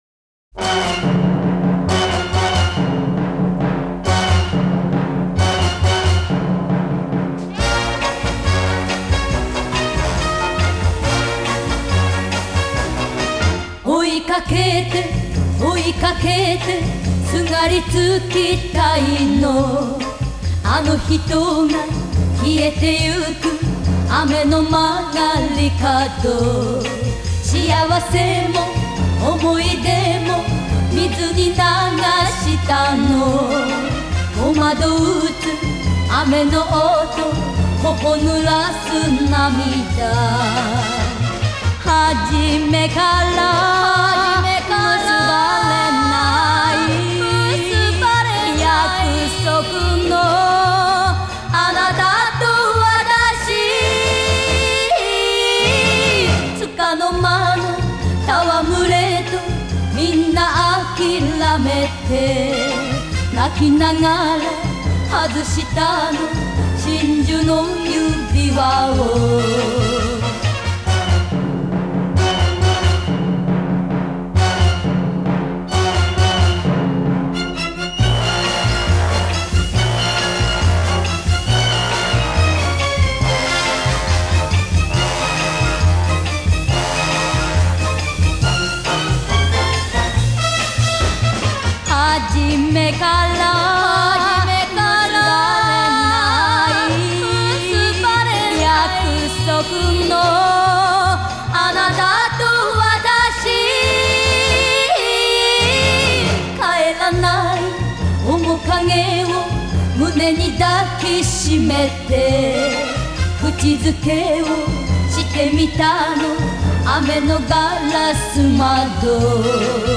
Звук неплохой. Японский ремастеринг.
Жанр: Classic Pop